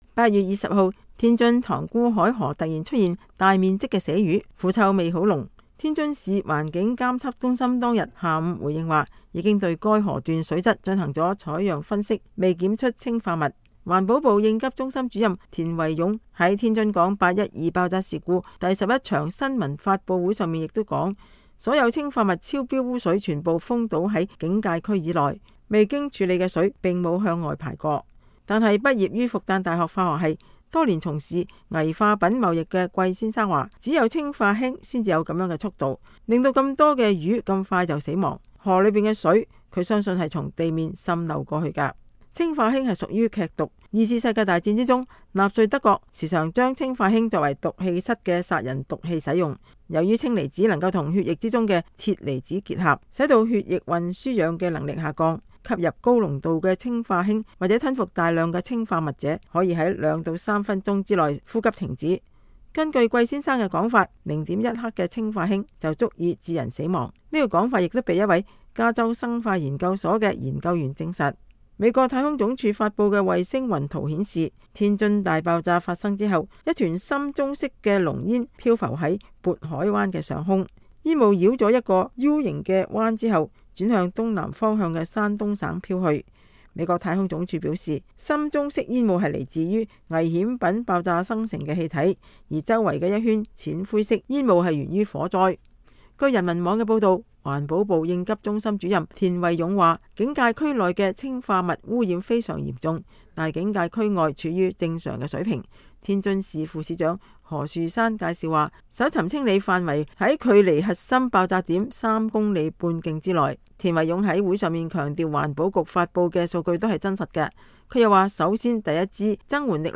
“812”天津港爆炸事故已經過去九天，諸多問題仍然得不到解答。美國之音採訪了多年從事危化品貿易的專業人士，對事故原因和影響做出簡單分析。